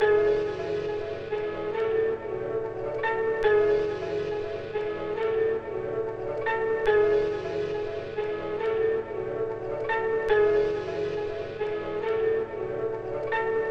陷阱钢琴2
描述：ap钢琴140bpm
Tag: 140 bpm Trap Loops Piano Loops 2.31 MB wav Key : Unknown